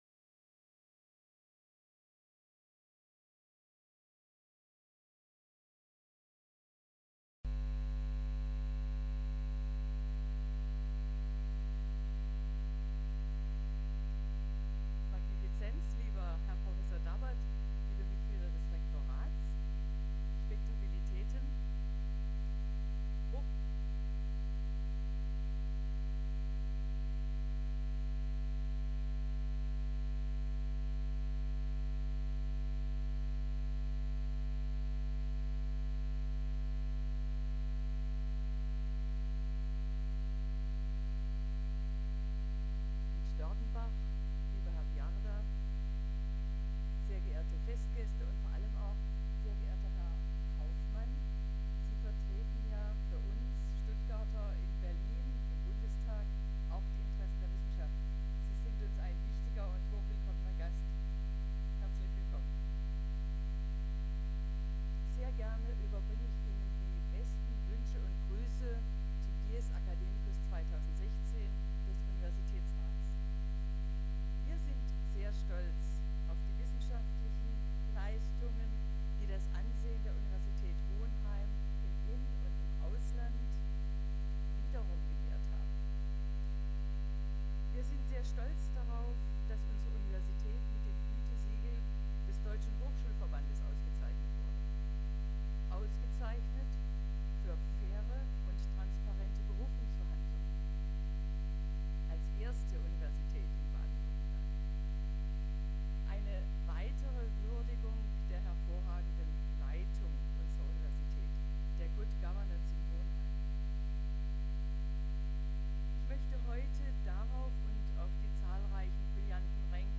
Leider gab es technische Probleme bei der Aufnahme - den Brummton und das fehlende Ende des Festvortrags bitten wir zu entschuldigen.